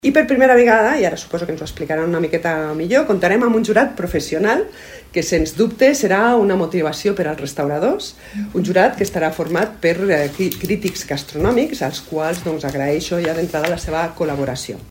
Una altra de les novetats d’aquesta edició que ha presentat la cònsol major d’Ordino, Maria del Mar Coma, és la incorporació d’un jurat professional.